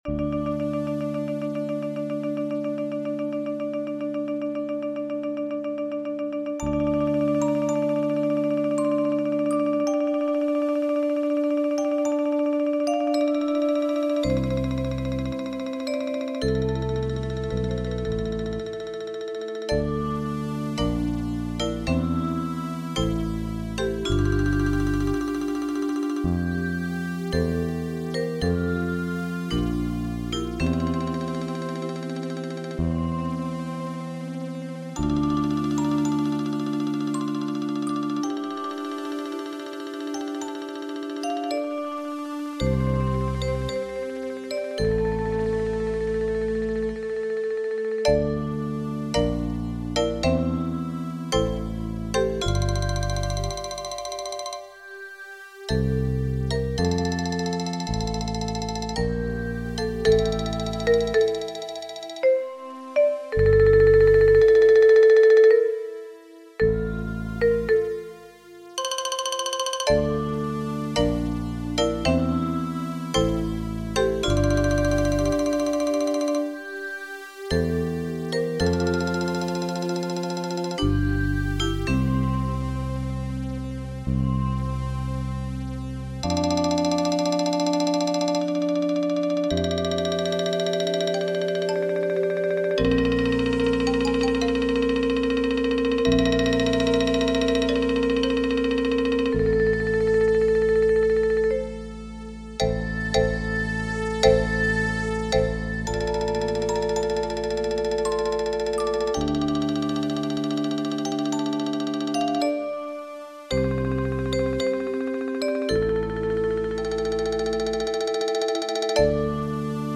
Mallet-Steelband Muziek
Xylofoon Vibrafoon Marimba Bas Gitaar Synthesizer